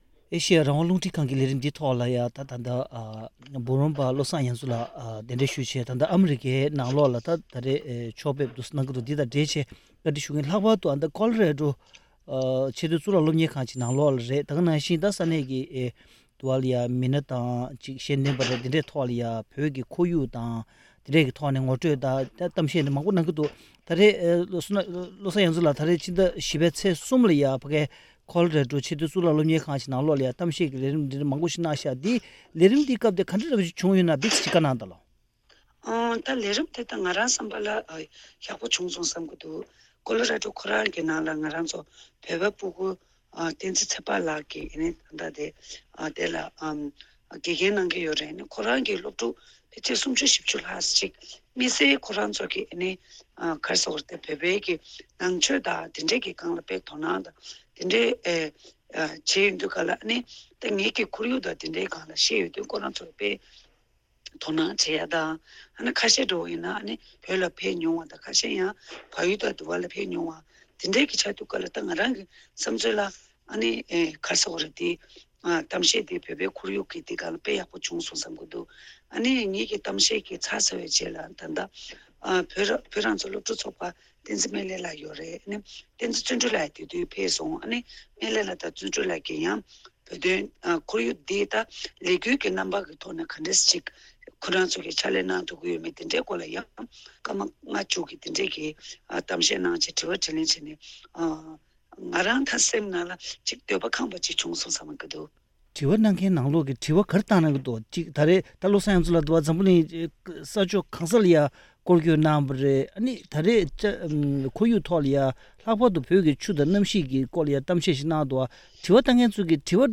བཅར་དྲིའི་ལས་རིམ་འདི་ནང་།